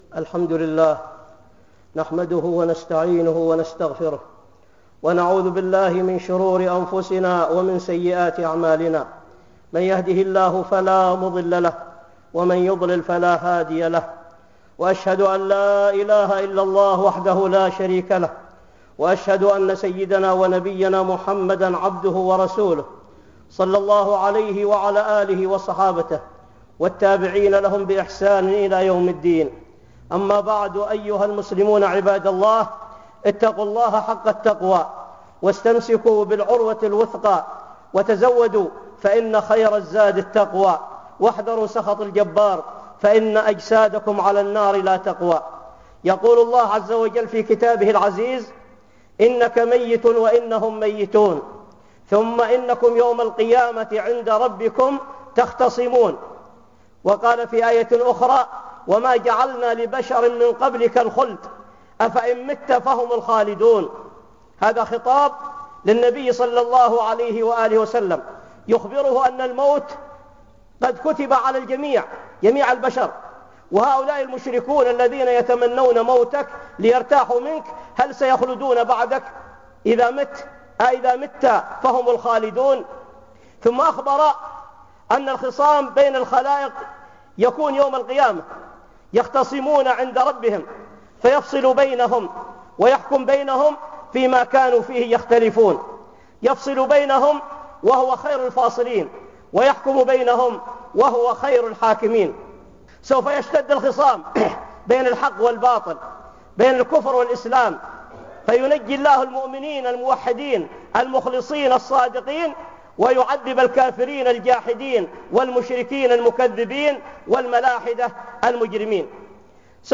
(خطبة جمعة) عند ربكم تختصمون